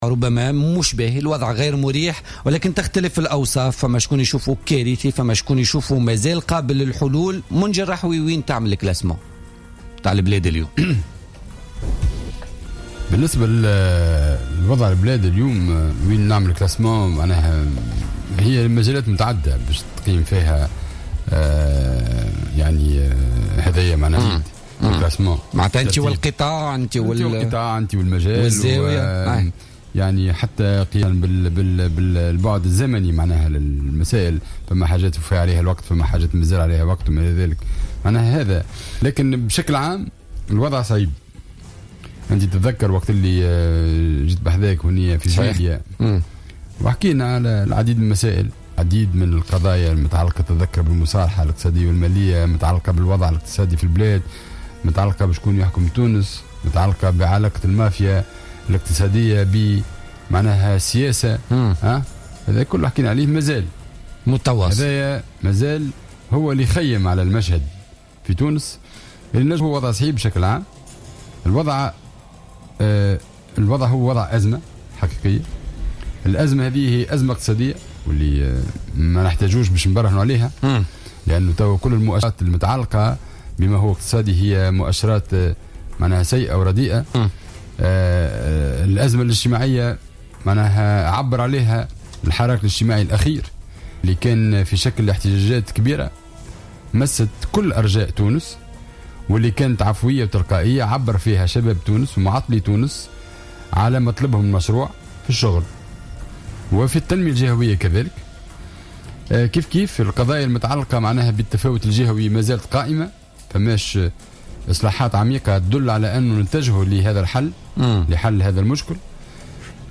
أكد النائب عن الجبهة الشعبية بمجلس نواب الشعب منجي الرحوي ضيف بوليتيكا اليوم...